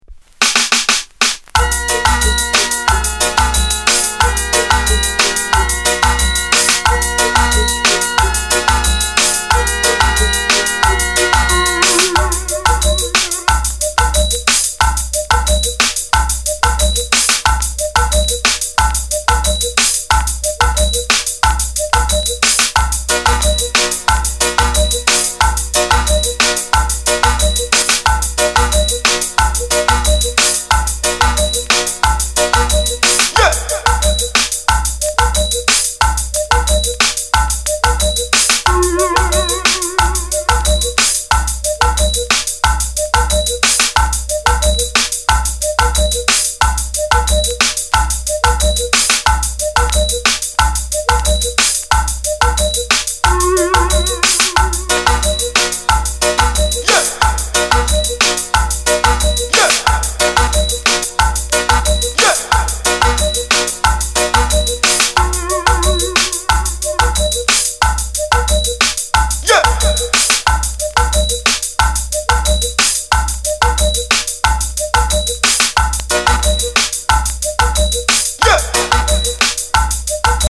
Genre: Reggae / Dancehall